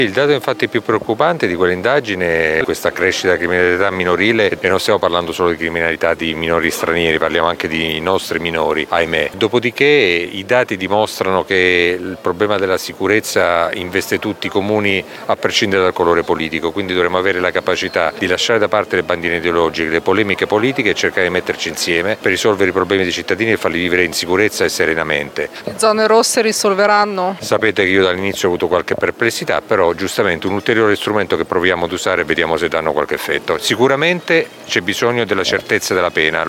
Il sindaco Massimo Mezzetti commenta così l’aumento della criminalità giovanile: